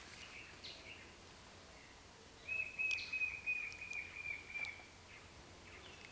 White-shouldered Fire-eye (Pyriglena leucoptera)
Life Stage: Adult
Location or protected area: Reserva Privada y Ecolodge Surucuá
Condition: Wild
Certainty: Recorded vocal
Batara-negro.mp3